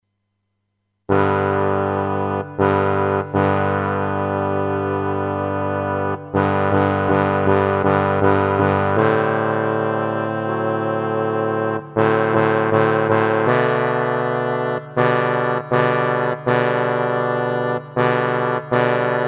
note: afin de ménager la bande passante, les fichiers en écoute immédiate sont encodés en mono, 22 KHz, 32 Kbps, ... ce qui signifie que leur qualité n'est pas "excellentissime"!
version en sol majeur
otche--sol -midi-B2.mp3